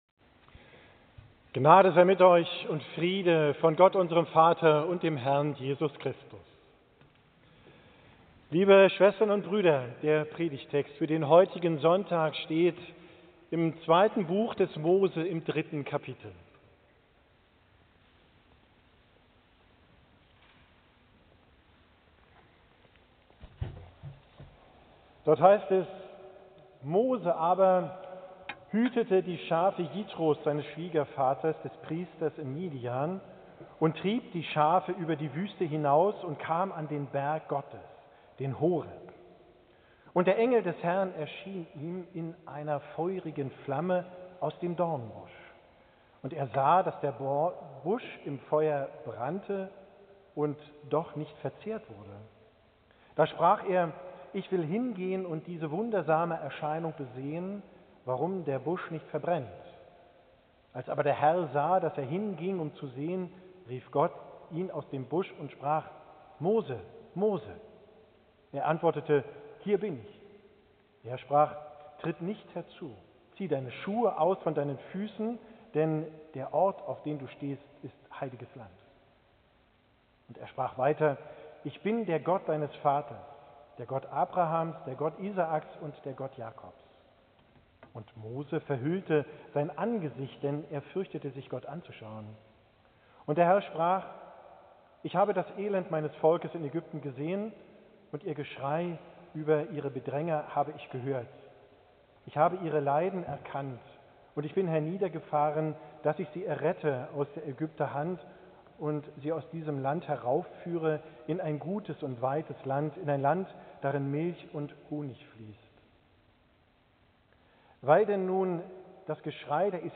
Predigt vom letzten Sonntag nach Epipahnias, 2.
Einweihung des neuen Gemeindehauses